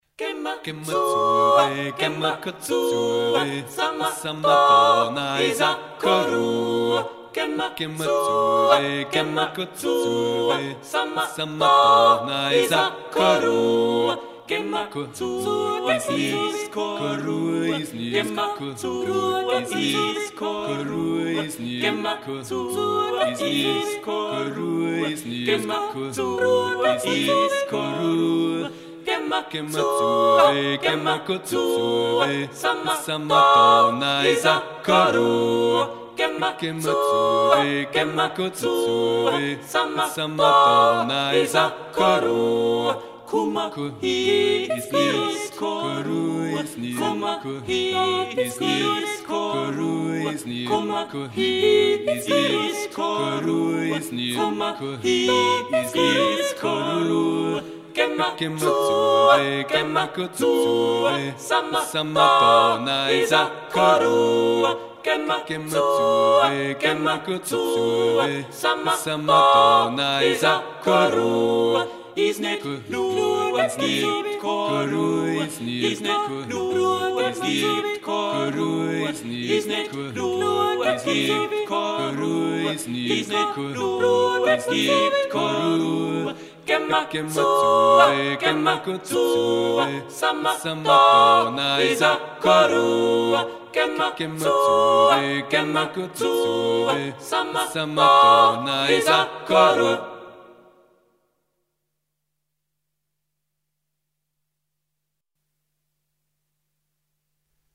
Another Bavarian song